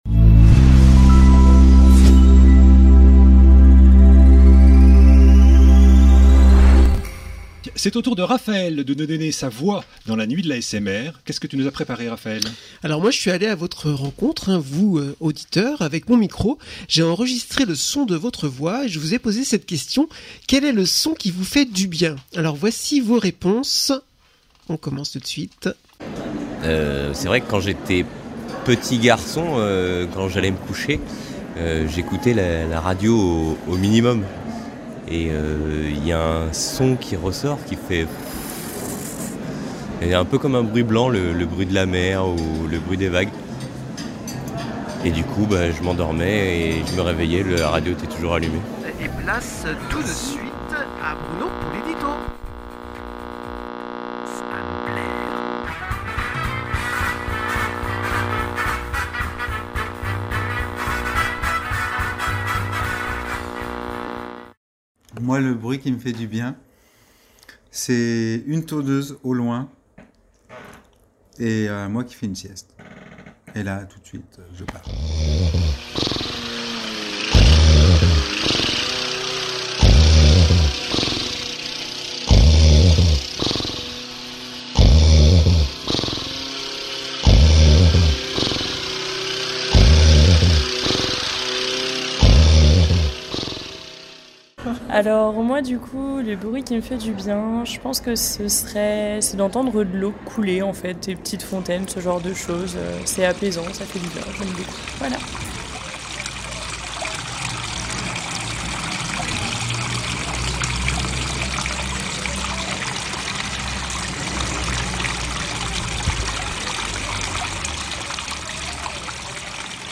113 Extraits asmr
113-asmr_vos_sons_1.mp3